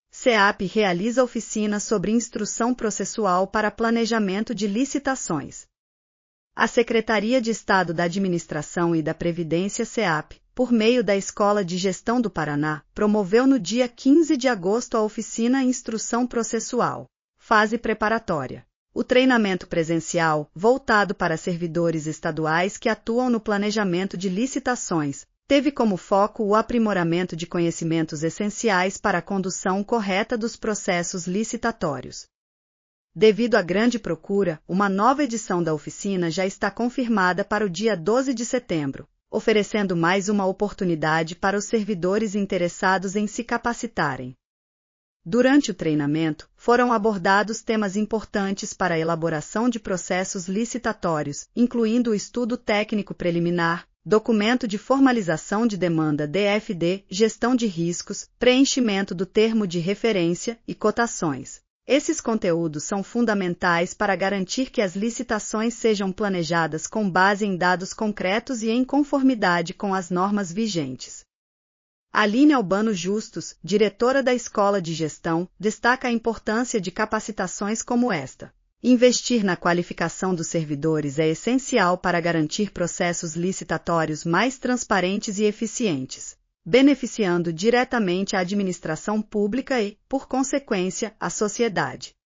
audionoticia_oficina_licitacoes_1.mp3